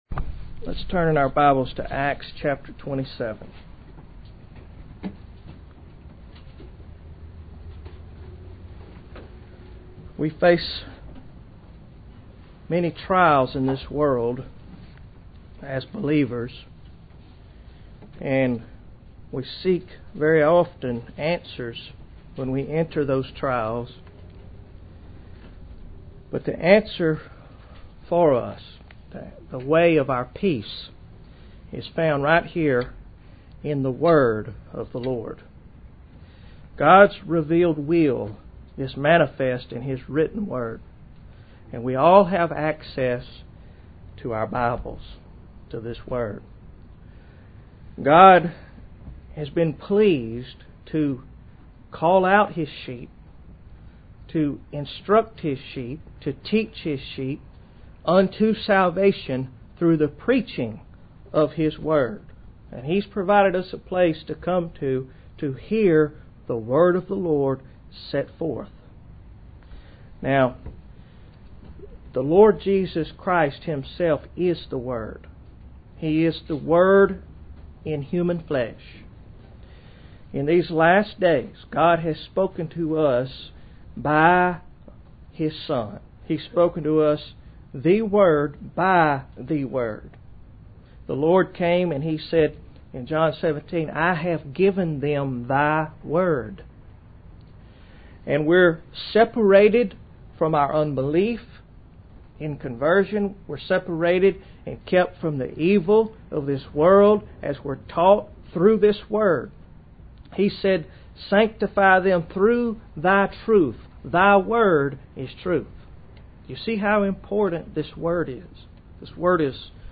Synopsis Here we examine a few of the things we must never let turn us from hearing and heeding the word of the Lord. Date 26-Aug-2010 Series Acts 2007 Article Type Sermon Notes PDF Format pdf Word Format doc Audio HI-FI Listen: Hear & Heed the Word (32 kbps) Audio CD Quality Listen: Hear & Heed the Word (128 kbps) Length 58 min. Series: Acts Title: Hear and Heed the Word Text: Acts 27: 9-14 Date: August 26, 2010 Place: SGBC, New Jersey God's revealed will is manifest in his written word.